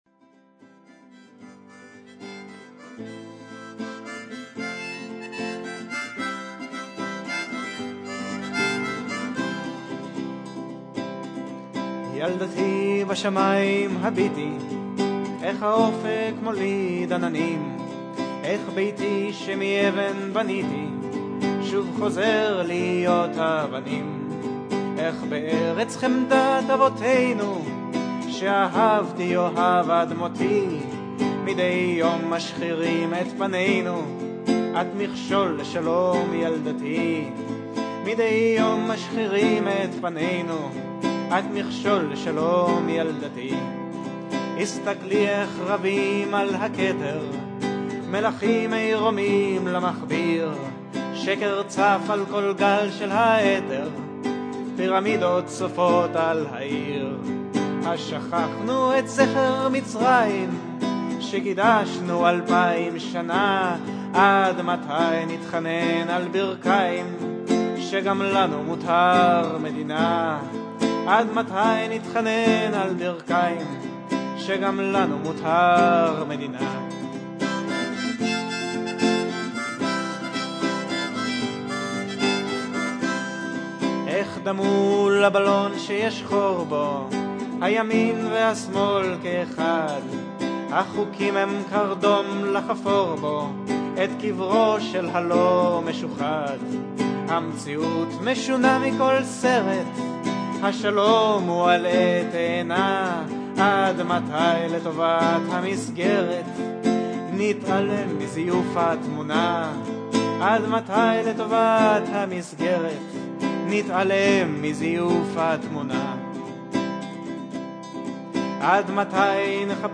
אבל המנגינה קצת משעממת כבר באמצע,
חוזר על עצמו כזה וקצת מעייף..